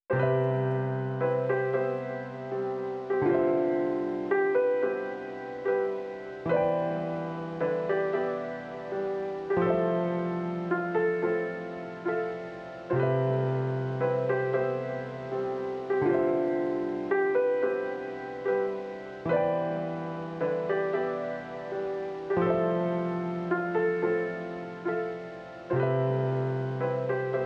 jazz keys 4.wav